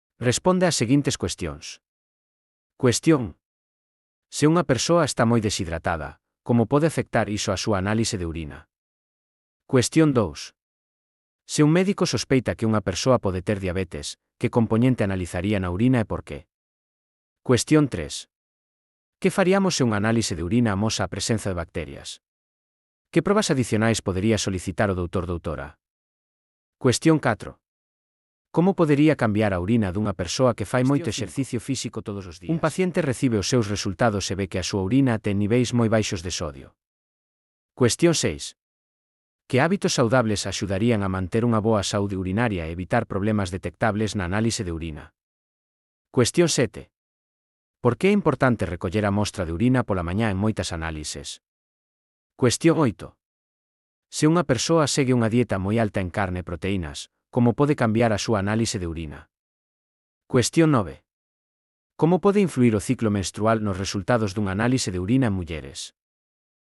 Elaboración propia coa ferramenta Narakeet. Transcrición de texto a audio dos exercicios (CC BY-SA)